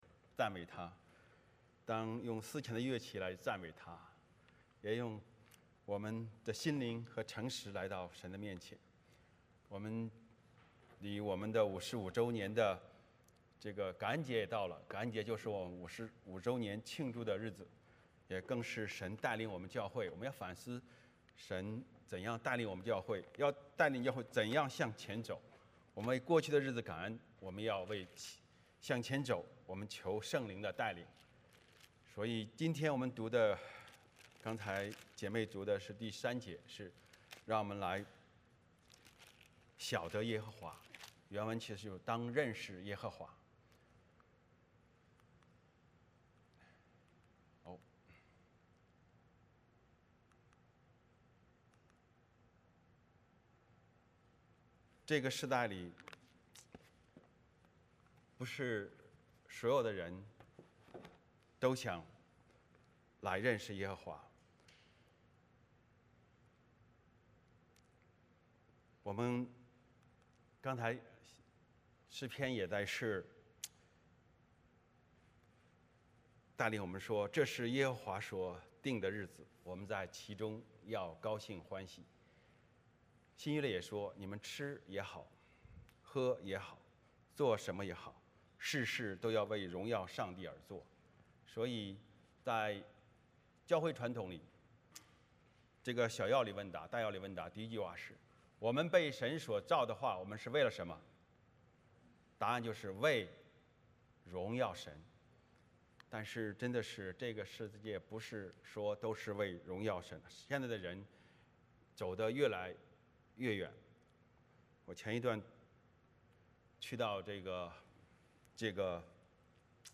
诗篇100篇 Service Type: 主日崇拜 欢迎大家加入我们的敬拜。